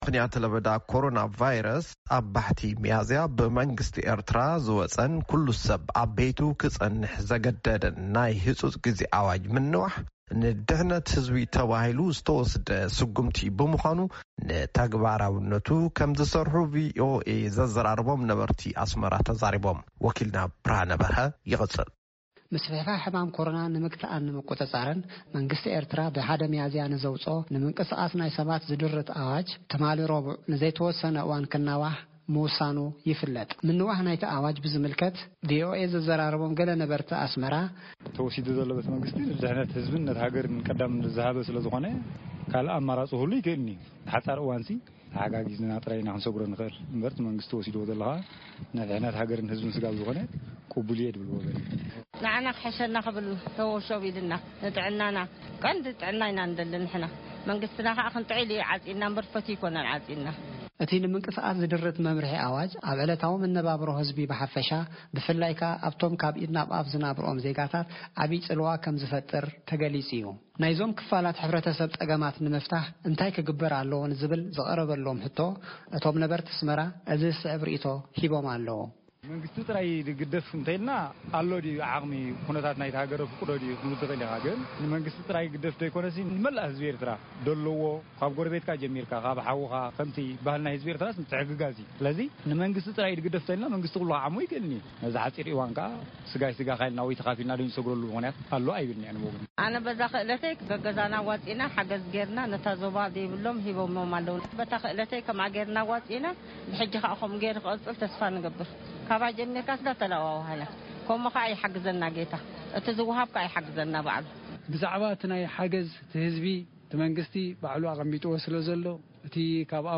ብምኽንያት ለበዳ ኮሮና ቫይረስ 1 ሚያዝያ ዝወጸኩሉ ሰብ ኣብ ቤቱ ክጸንሕ ዘገድድናይ ህጹጽ ግዜ ኣዋጅ ምንዋሕ ንድሕነት ህዝቢ ተባሂሉ ዝተወስደ ስጉምቲ ብምዃኑ ንተግባራውነቱ ክምዝሰርሑ ድምጺ ኣሜሪካ ዘዘራረቦም ነበርት ኣስመራ ተዛሪቦም ።ብ”ምኽንያት ምንዋሕ ናይ ኣዋጅ ንዝህሰዩ ካብ ኢድ ናብ ኣፍ ዝናብርኦም ዜጋታት ምሕጋዝ ናይ ኩሉ ሓላፍናት እዩክብሉ እቶም ወሃብቲ ርእይቶ ተዛሪቦም